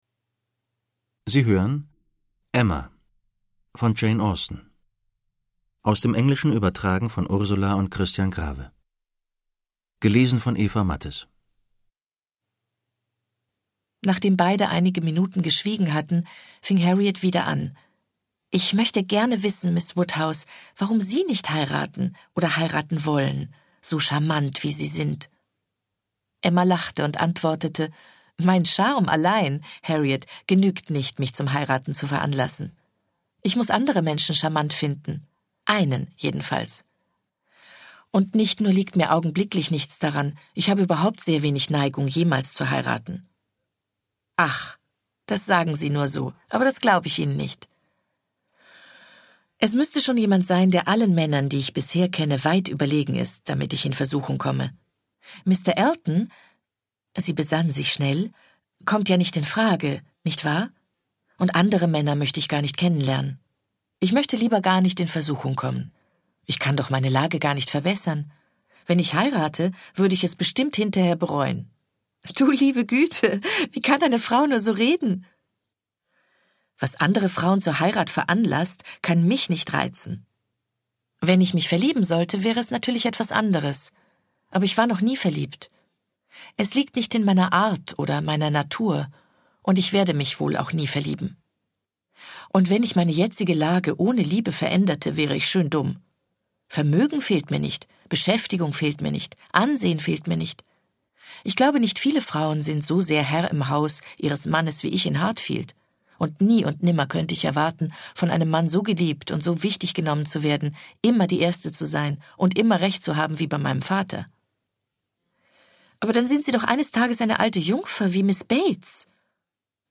Eva Mattes (Sprecher)
2013 | 2. Auflage, Ungekürzte Ausgabe
Schlagworte England • Heirat • Heiraten • Historische Liebesromane • Hörbuch; Literaturlesung • Kleinstadt • Kuppeln • Liebe • Romantik